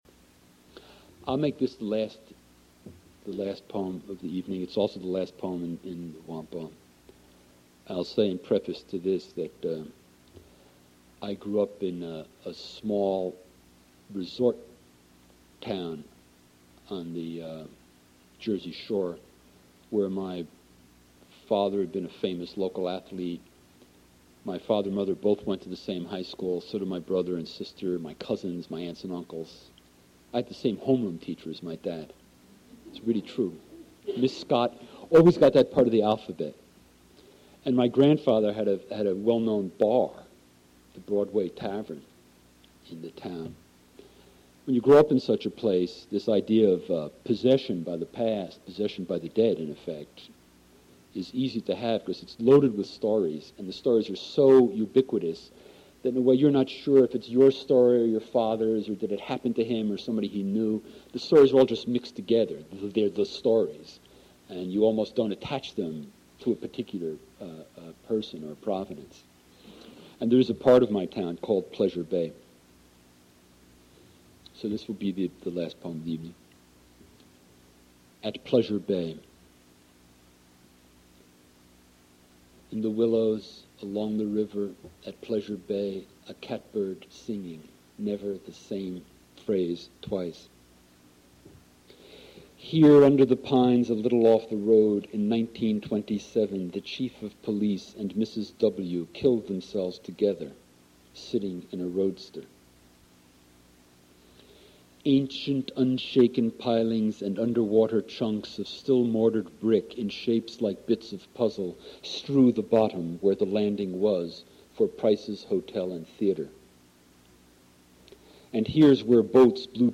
Spring Reading Series
Modern Languages Auditorium